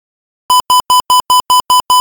ALR56_Launch.ogg